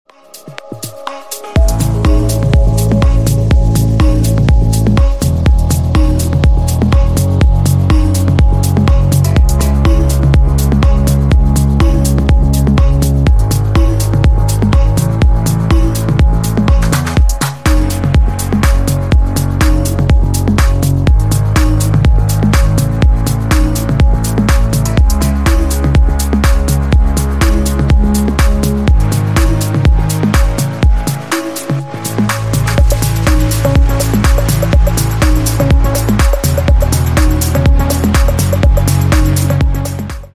Клубные Рингтоны » # Рингтоны Без Слов
Рингтоны Электроника